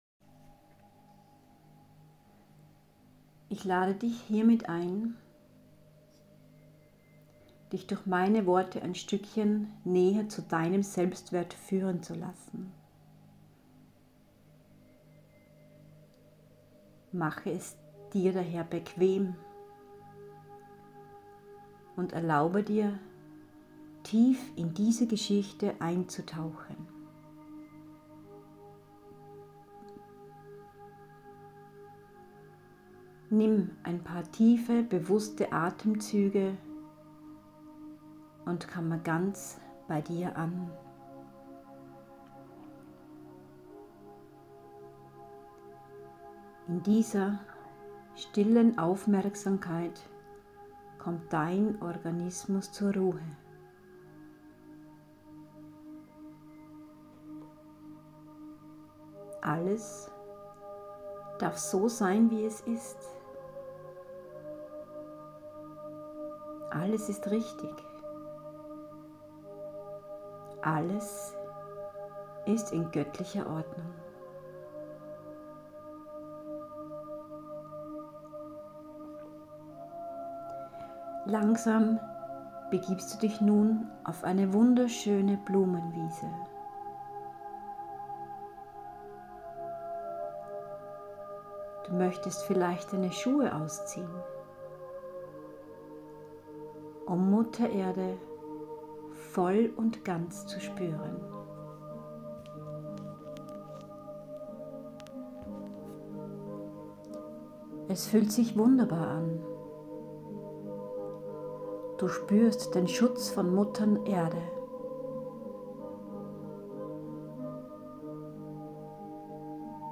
Ein Geschenk für Dich - Seelennahrungmeditation
Meditation-Selbstwert.m4a